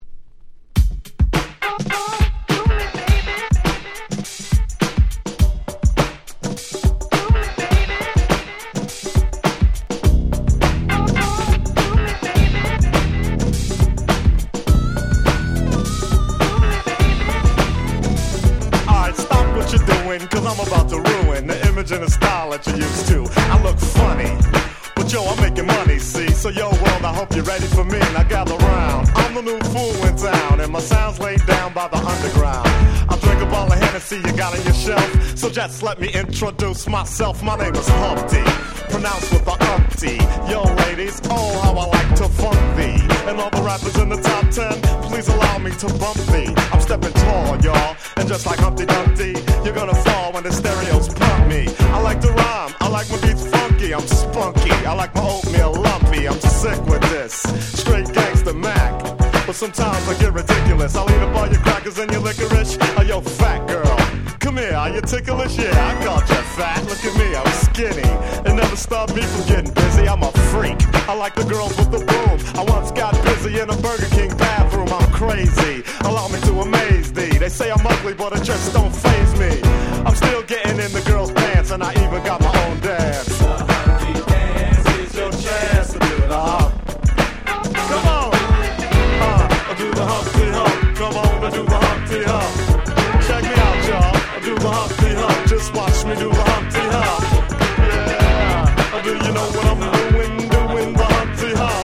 92' Smash Hit Hip Hop !!
90's デジタルアンダーグラウンド Boom Bap ブーンバップ